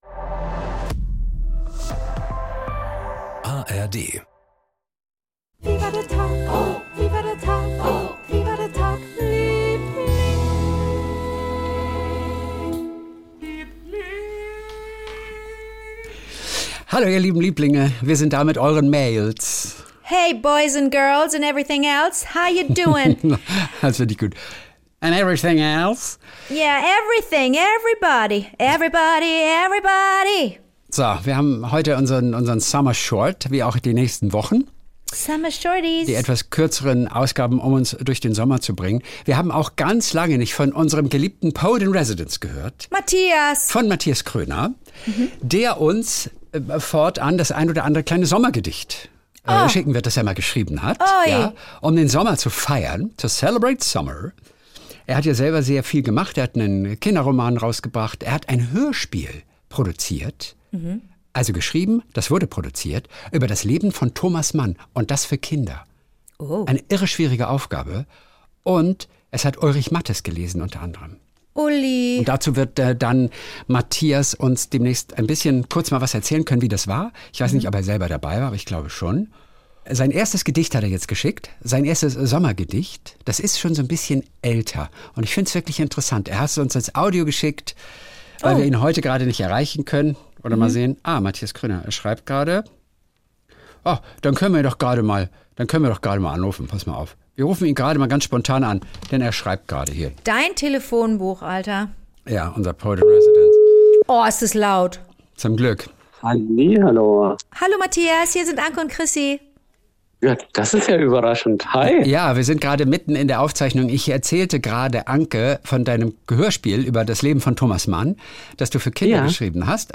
1 KW 9 (Do) Schneller als ne Schnatterflatter (Hörererektionen) 30:53 Play Pause 13d ago 30:53 Play Pause 나중에 재생 나중에 재생 리스트 좋아요 좋아요 30:53 Jeden Montag und Donnerstag Kult: SWR3-Moderator Kristian Thees und seine beste Freundin Anke Engelke erzählen sich gegenseitig ihre kleinen Geschichtchen des Tages.